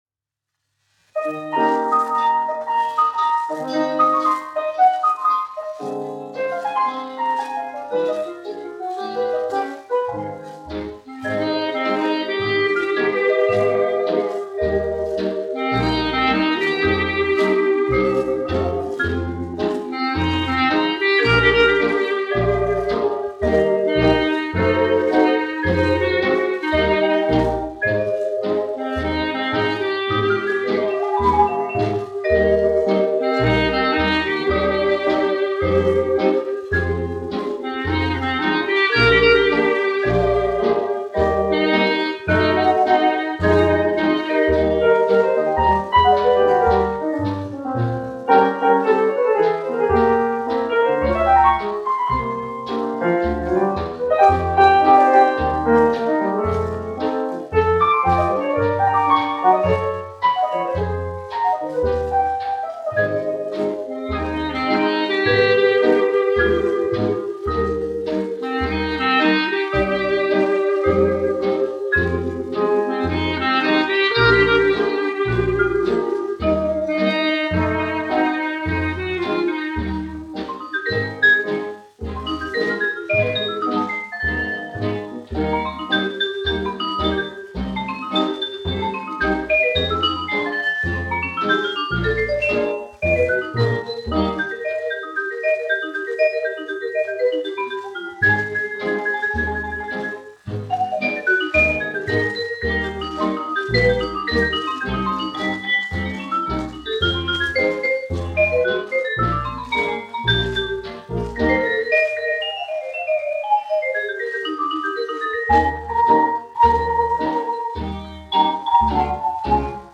1 skpl. : analogs, 78 apgr/min, mono ; 25 cm
Džezs
Populārā instrumentālā mūzika
Skaņuplate
Latvijas vēsturiskie šellaka skaņuplašu ieraksti (Kolekcija)